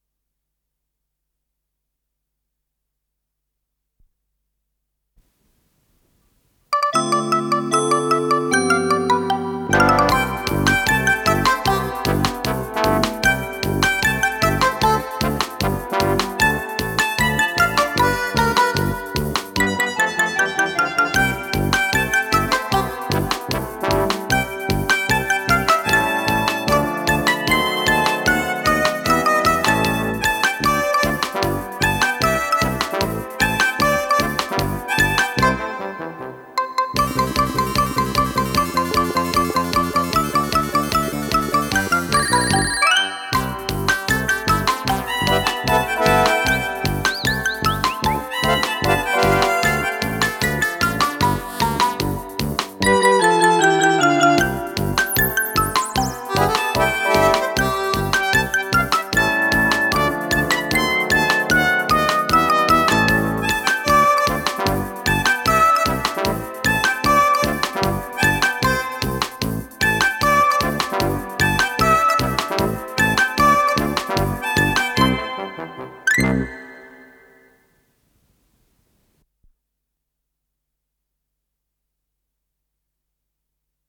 с профессиональной магнитной ленты
ПодзаголовокОригинал - для фортепиано, до мажор